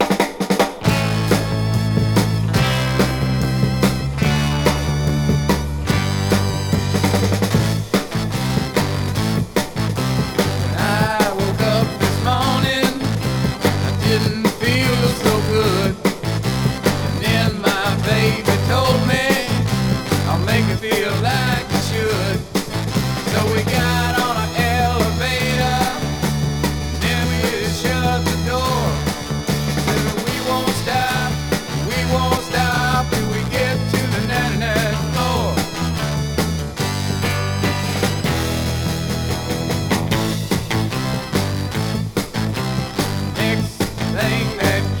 モッドなテイスト大盛りでこれまたある種の青春の香りが吹き上げます。
Soul, Funk　UK　12inchレコード　33rpm　Mono/Stereo